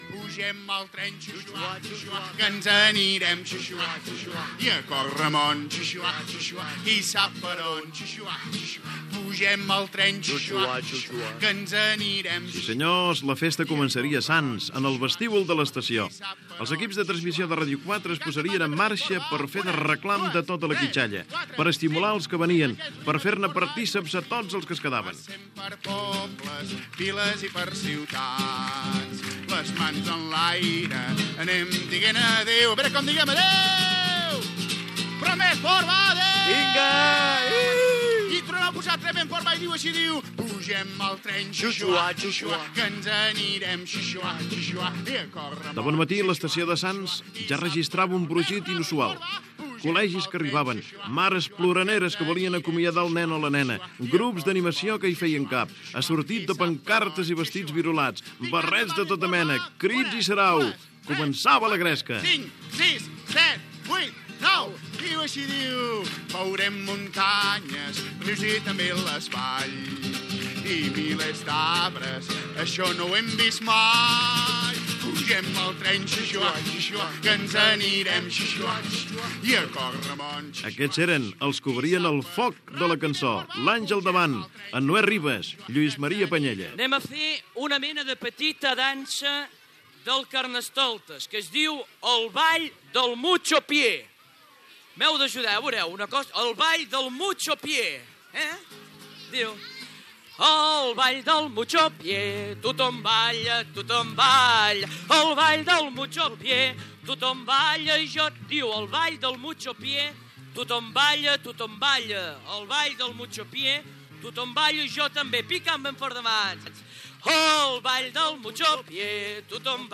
Grups d'animació i cantants que hi van participar i ambient que s'hi va viure.
Infantil-juvenil